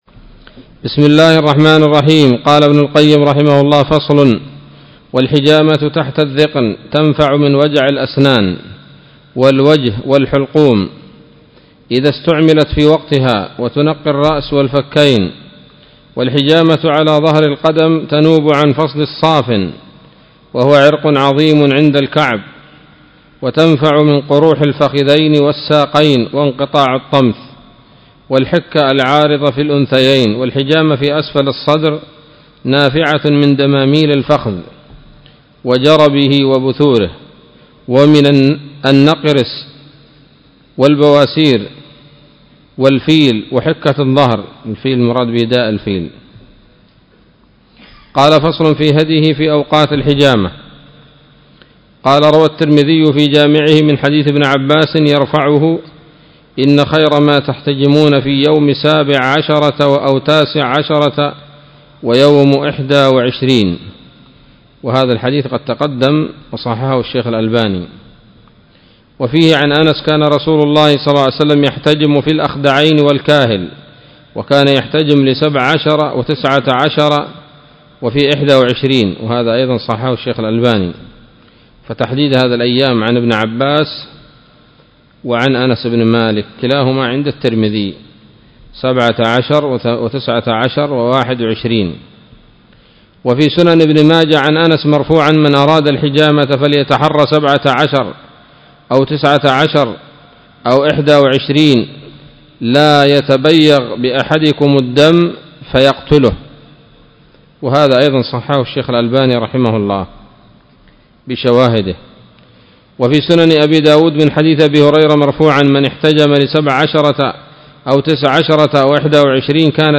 الدرس السادس عشر من كتاب الطب النبوي لابن القيم